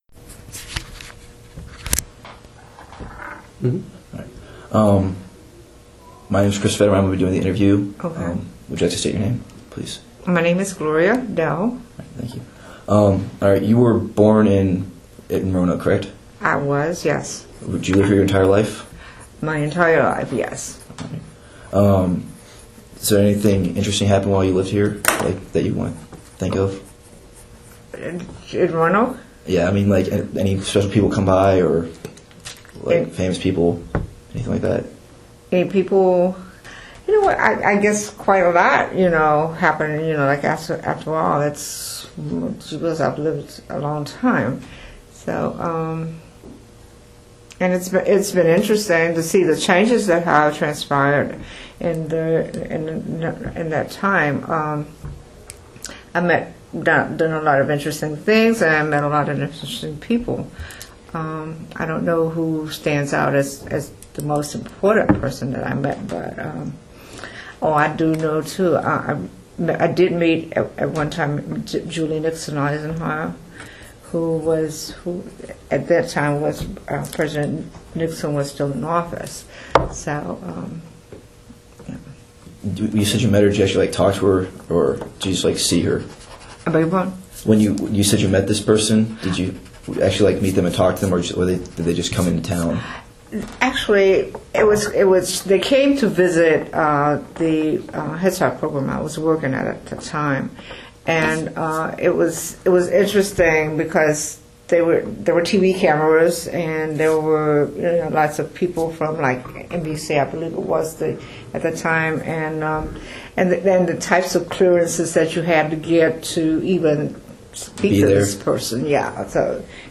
Neighborhood History Interview
Location: Lucy Addison Middle School